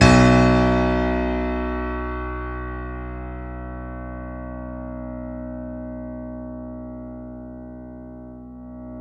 Index of /90_sSampleCDs/Roland L-CD701/KEY_YC7 Piano ff/KEY_ff YC7 Mono